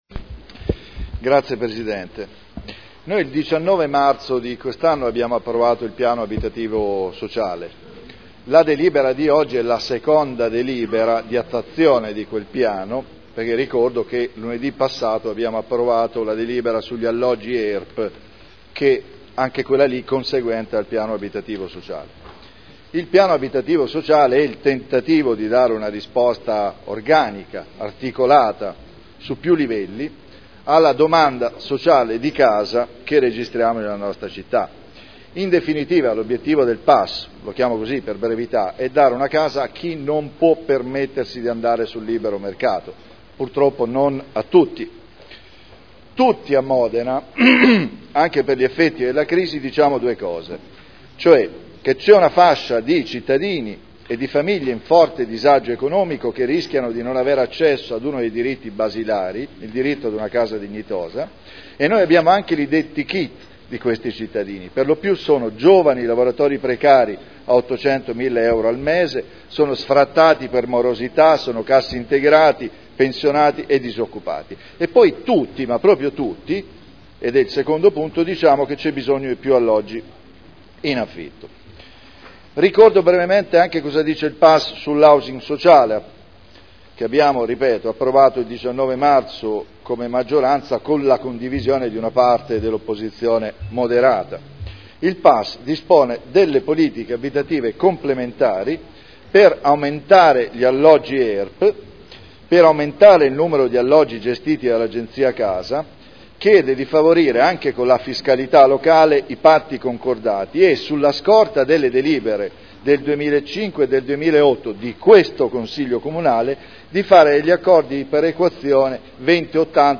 Paolo Trande — Sito Audio Consiglio Comunale
Ordine del giorno n° 37644 Pianificazione e riqualificazione della città. Dibattito